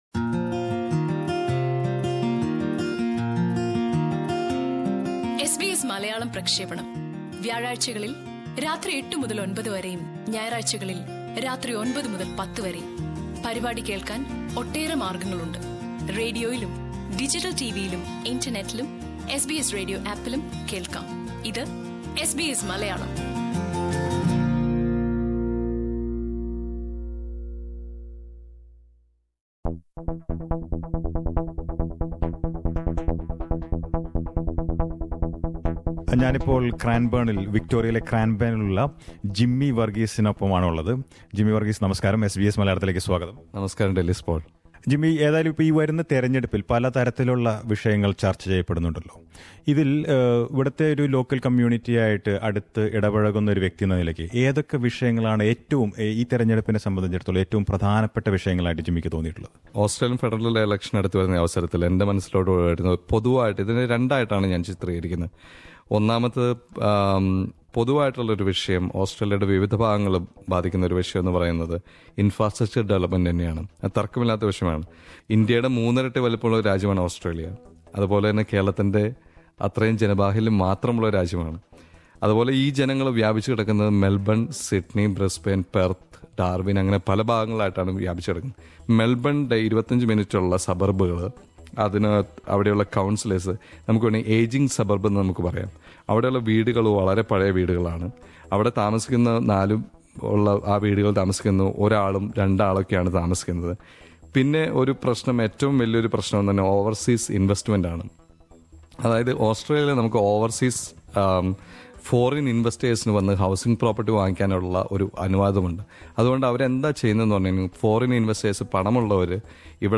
SBS Malayalam speaks to some listeners who are actively involved with their community about subjects that matter the most in this election.